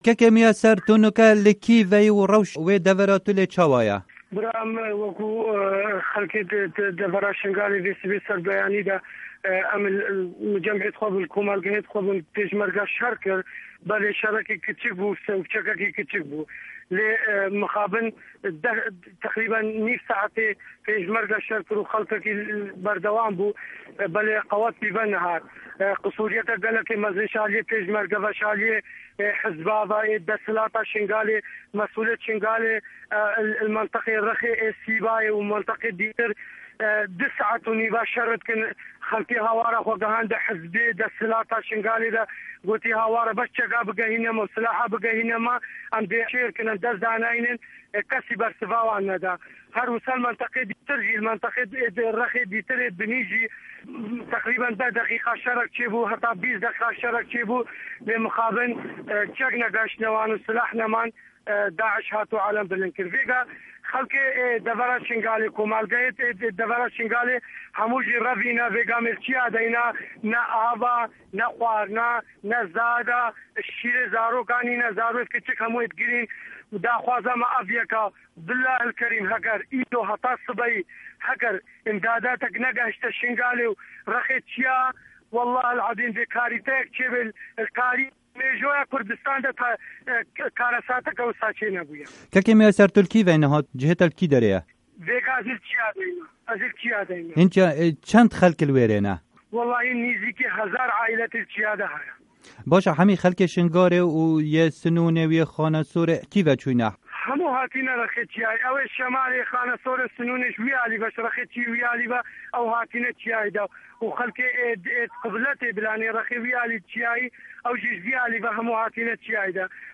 Di vê hevpeyvînê de, du kesên ji Şingalê behsa rewşa xwe ya mirovahî dikin.